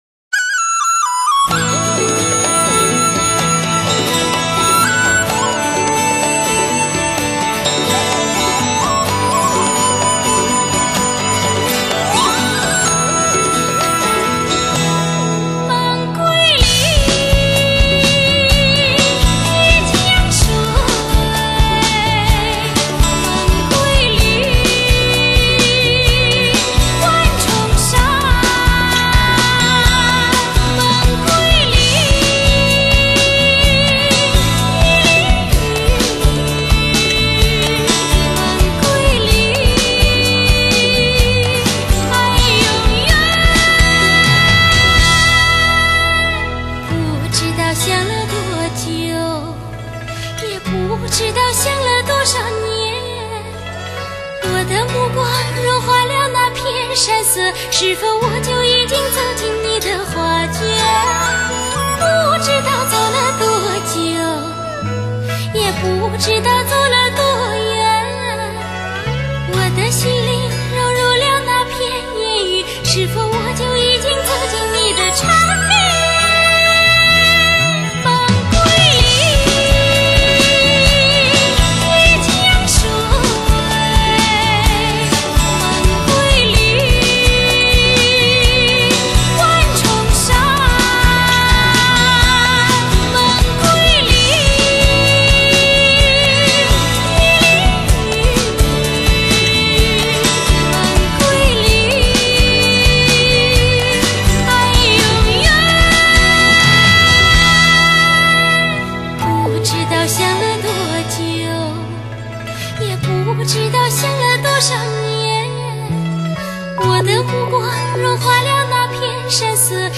（试听为低品质wma文件，下载为320k/mp3）